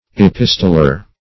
Epistolar \E*pis"to*lar\